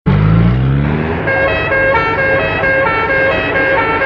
• BOAT ENGINE AND MELODIC HORN.mp3
A melodic foghorn yelled by a small ship in near waters.
boat_engine_and_melodic_horn_bvw.wav